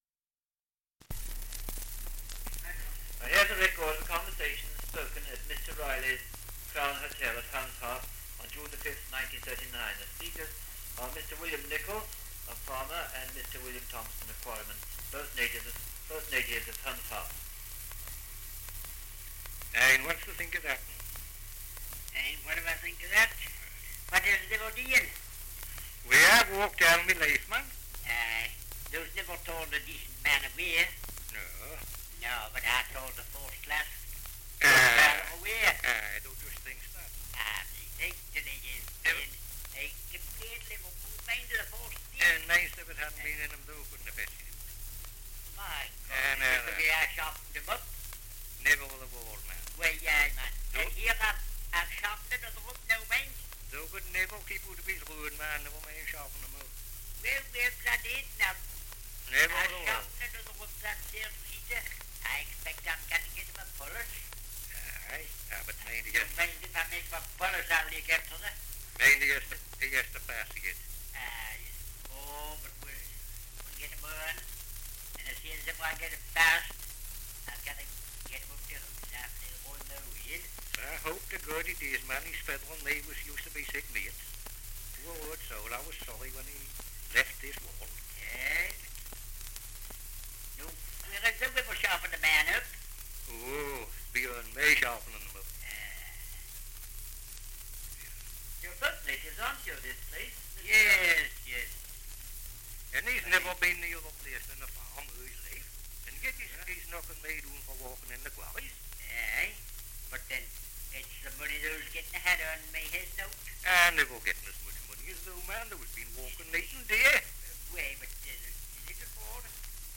Dialect recording in Humshaugh, Northumberland
78 r.p.m., cellulose nitrate on aluminium